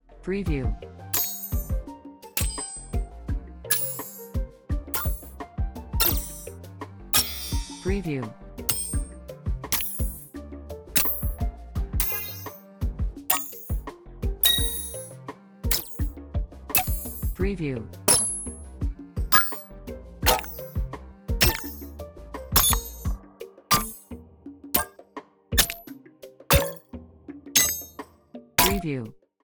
Sparkly Menu Button Click Sounds | Audio Sound FX | Unity Asset Store
Sparkly Menu Button Click Sounds.mp3